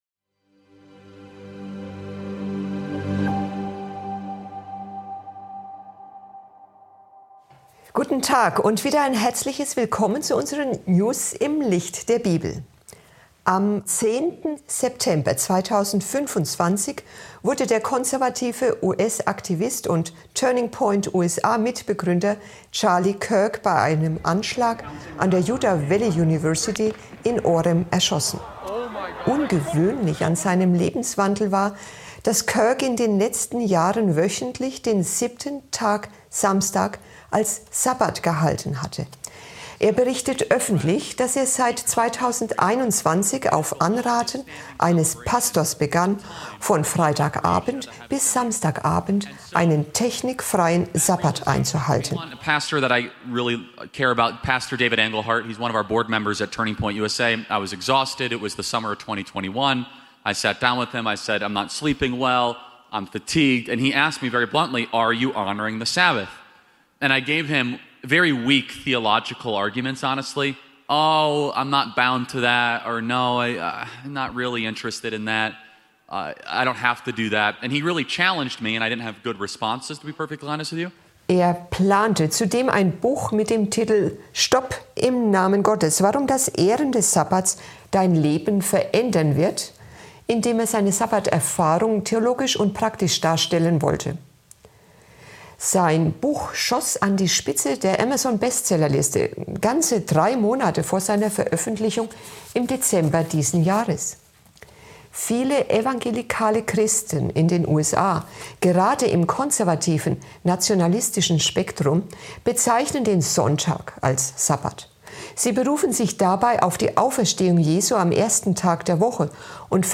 Kategorie News